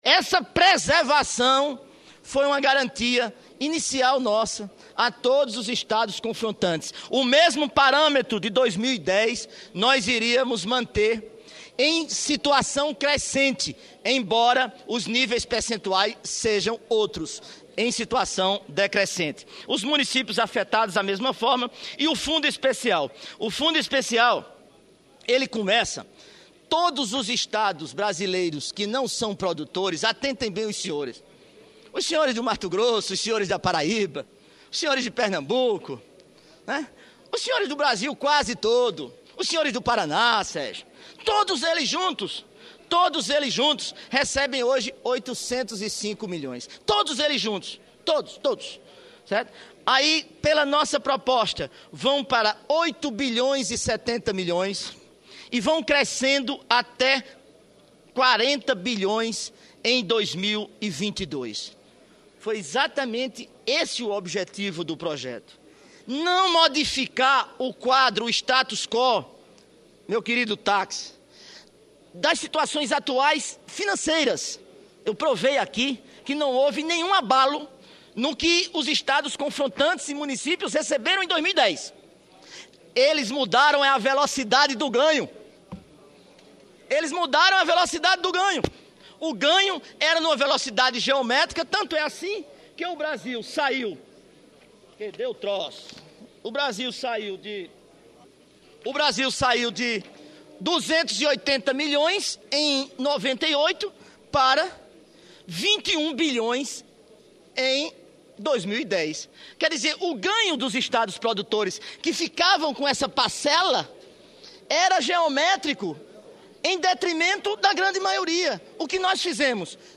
Pronunciamento do relator da matéria senador Vital do Rêgo - 3ª parte